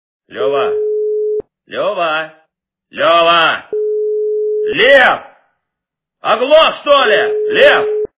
» Звуки » Именные звонки » Именной звонок для Льва - Лева, Лева, Лев оглох что ли Лев
При прослушивании Именной звонок для Льва - Лева, Лева, Лев оглох что ли Лев качество понижено и присутствуют гудки.